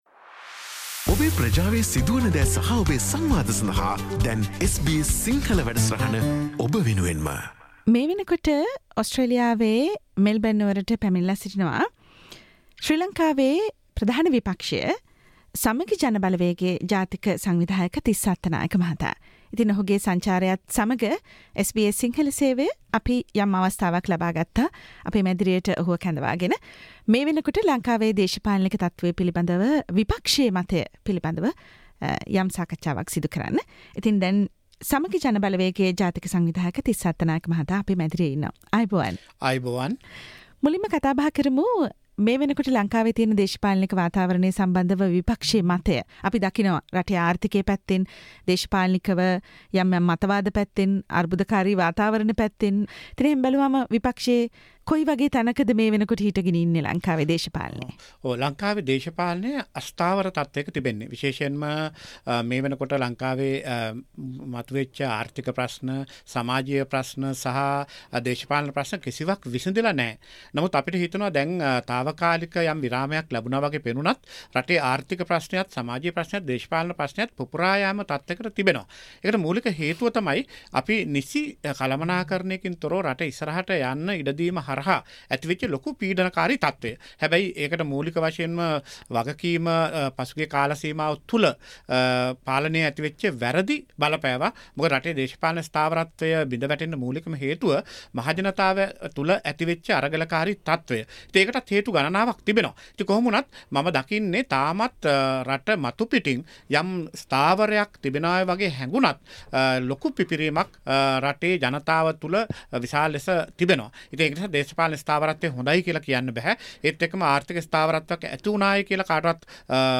SBS Sinhala Radio discussion with Mr. Tissa Attanayake, National Organizer of Samagi Jana Balavegaya, about the opposition's opinion on the current political situation in Sri Lanka